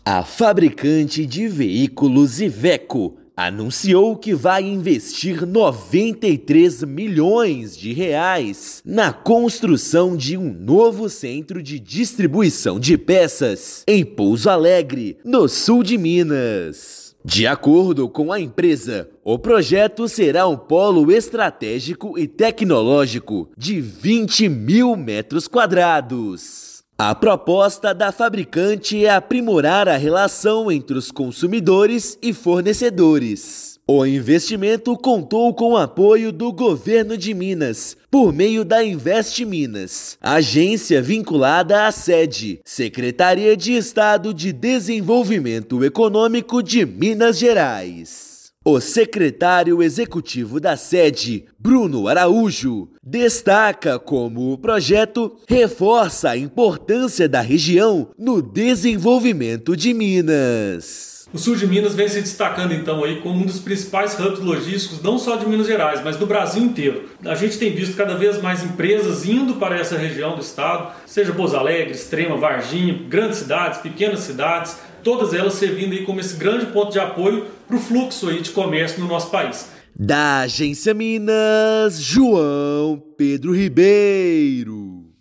Com apoio do Governo de Minas, novo empreendimento terá infraestrutura para otimizar operações logísticas de grupo automotivo. Ouça matéria de rádio.